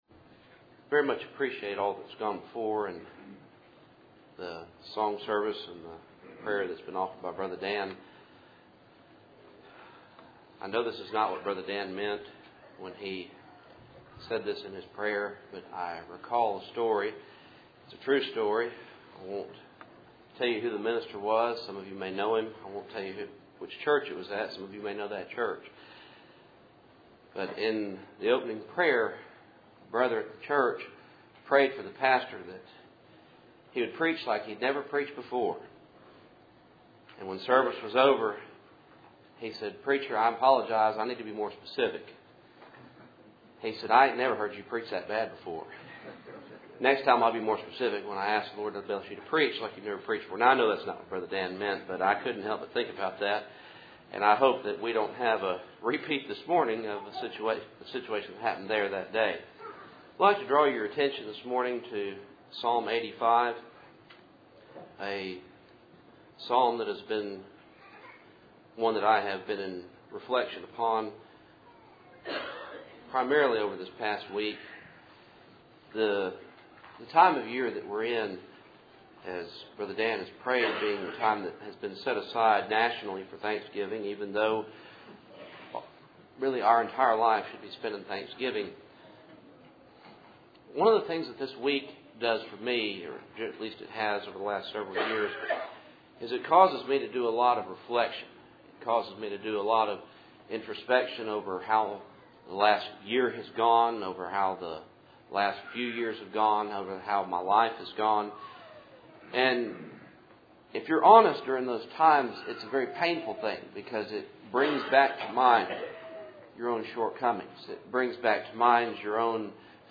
Cool Springs PBC Sunday Morning